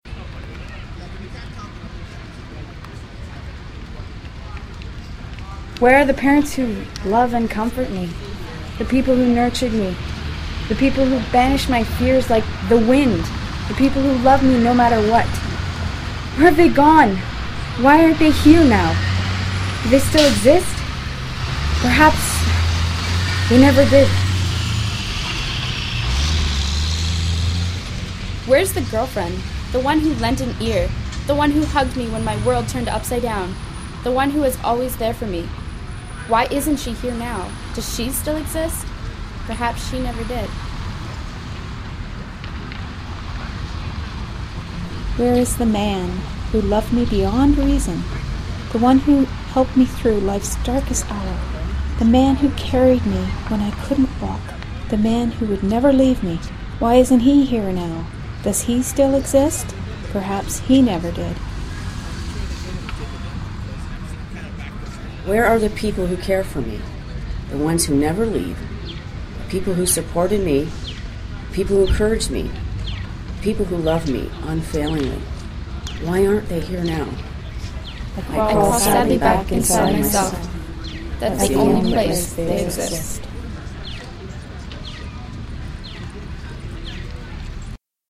So after making several recordings of various women reading the poem I decided this was the best way to go.